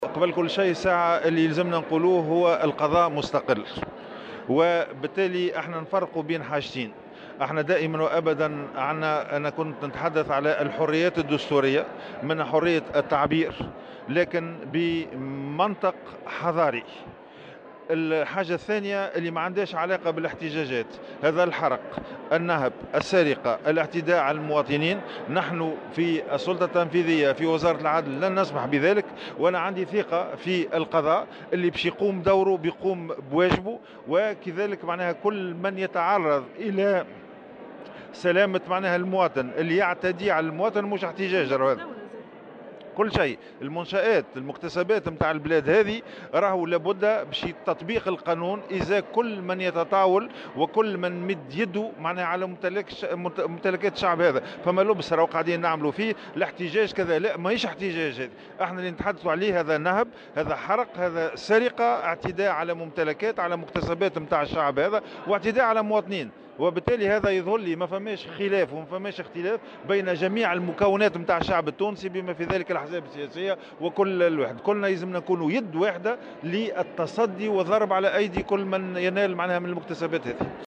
وأضاف في تصريح لمراسلة "الجوهرة أف أم" على هامش الافتتاح الرسمي لمحاضرات التمرين للسنة القضائية 2017/2018 أن حرية التعبير والاحتجاج السلمي حق يكفله الدستور، لكن لابد من تطبيق القانون ضدّ كل من يعتدي على الممتلكات العامة والخاصة.